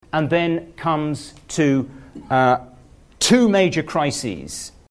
The word to may be pronounced strongly if it isn’t connected to an immediately following word. This can occur when the speaker hesitates: